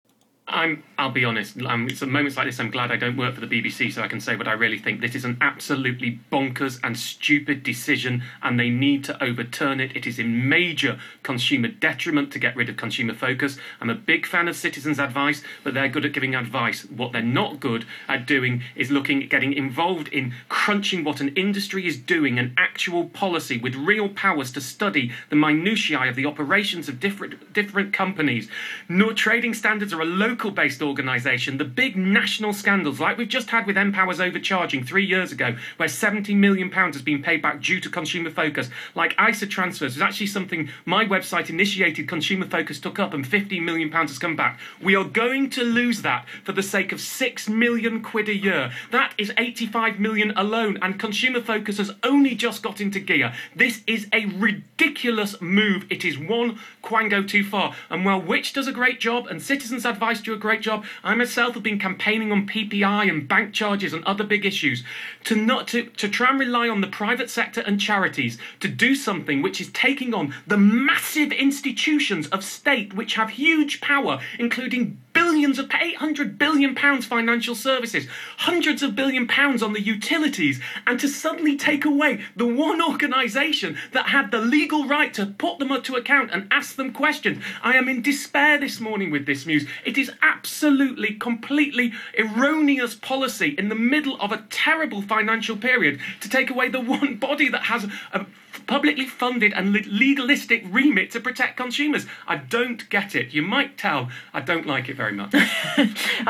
Financial journalist Martin Lewis tore into the decision today on Radio Five – well worth a listen.